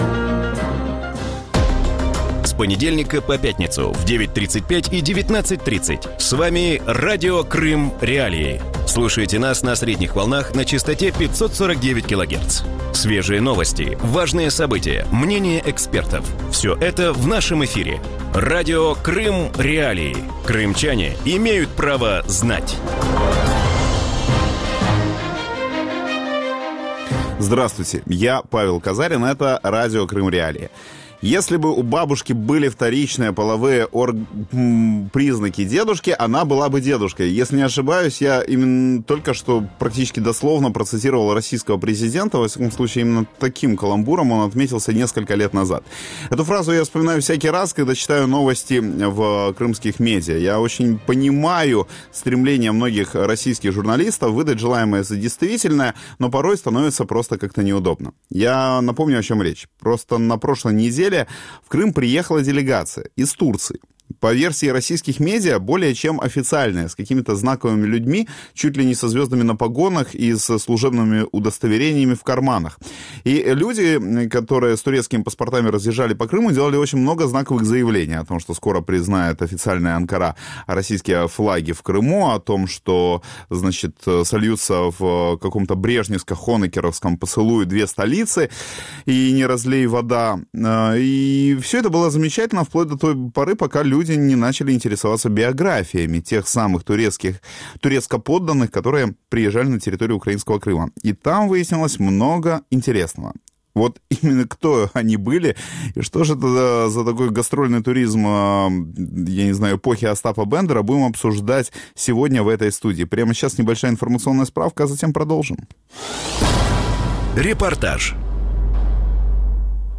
У вечірньому ефірі Радіо Крим.Реалії говорять про візит неофіційної турецької делегації до Криму. Як змінювалися відносини між Кримом і Туреччиною з моменту анексії, чи варто півострову очікувати нових інвестицій і чи може Туреччина визнати російський статус Криму?